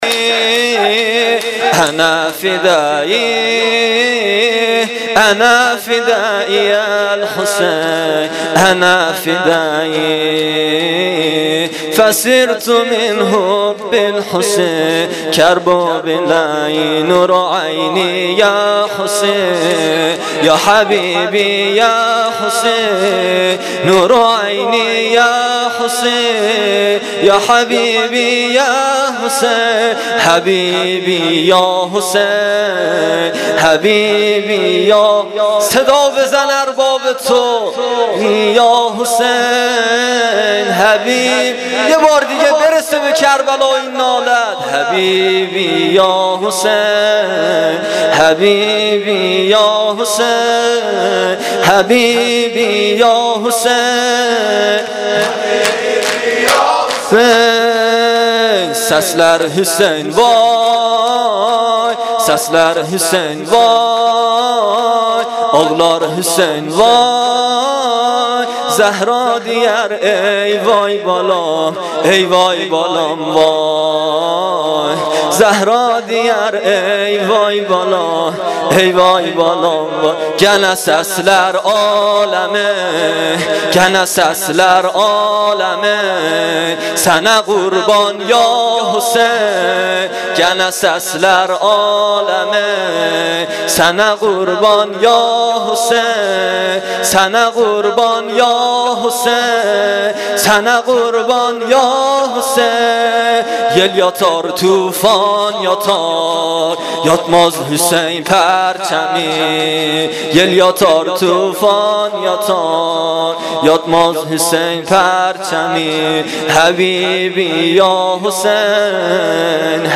شور - شام غریبان حسینی